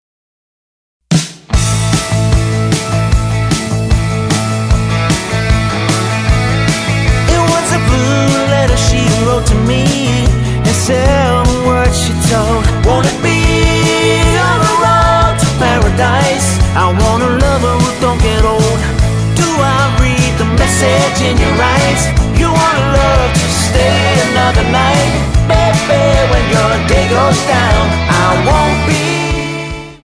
--> MP3 Demo abspielen...
Tonart:A Multifile (kein Sofortdownload.